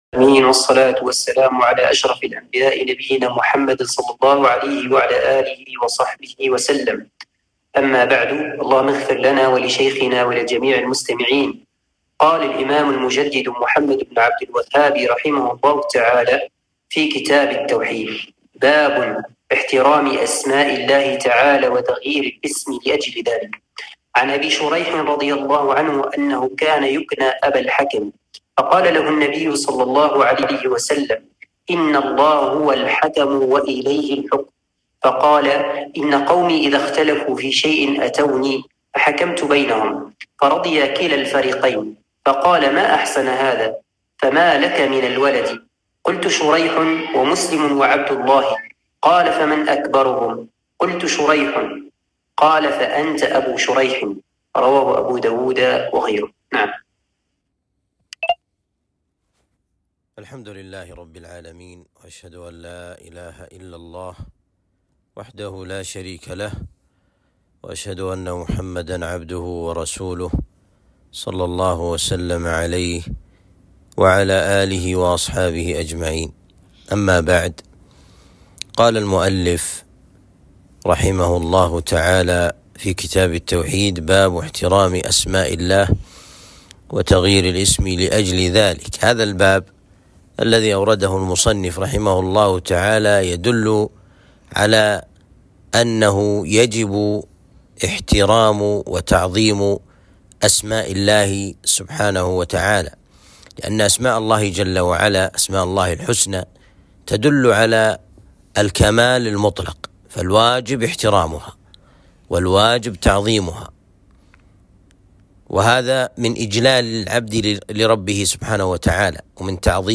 درس شرح كتاب التوحيد (45)